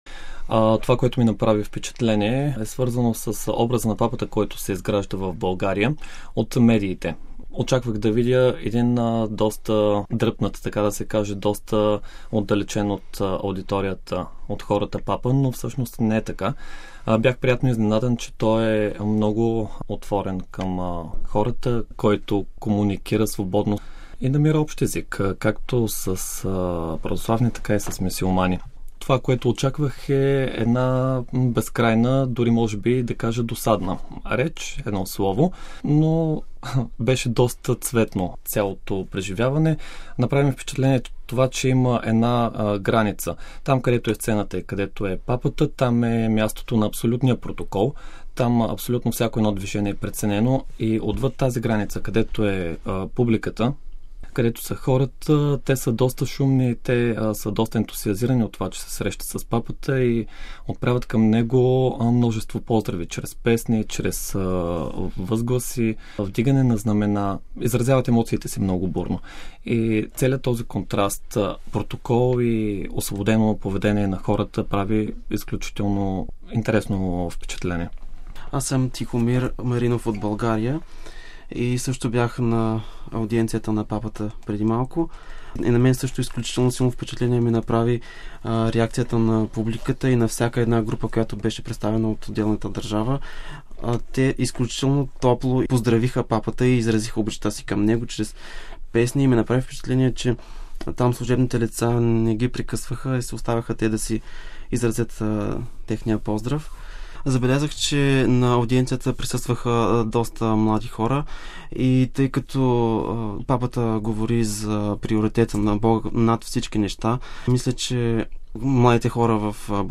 Сред присъстващите в аулата близо 7 хиляди души имаше поклонници и от България.